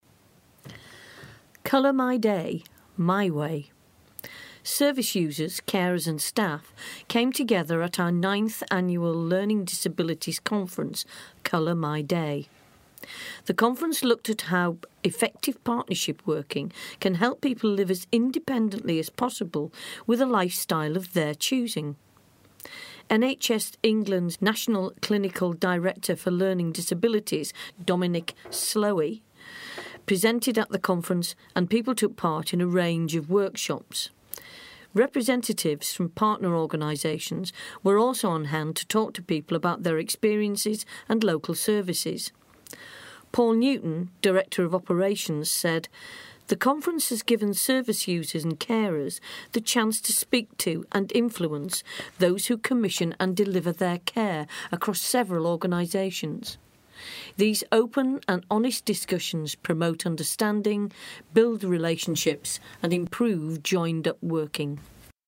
Service users, carers and staff came together at our ninth annual learning disabilities conference 'Colour my day'.